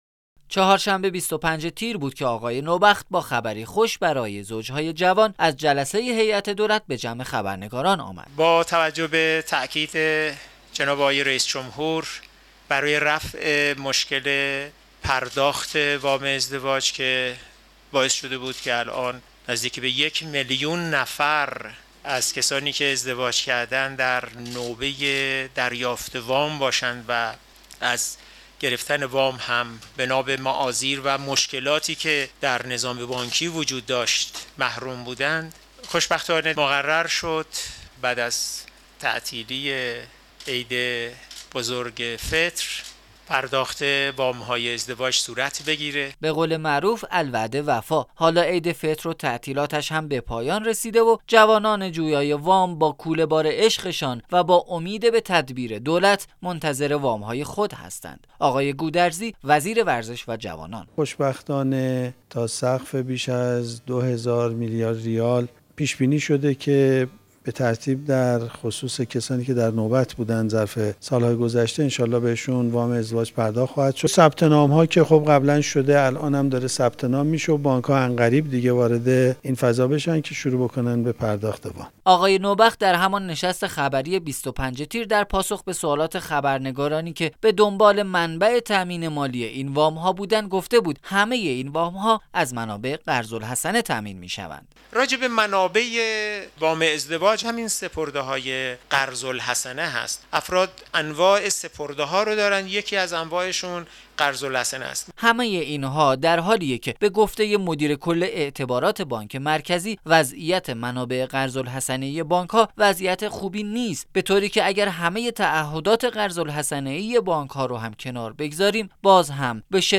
گزارش " شنیدنی" از سررسید قول دولت برای وام ازدواج - تسنیم